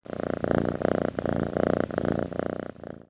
Звуки уведомлений Telegram
Мурлыканье кошки (прикольный звук для новых сообщений в Telegram)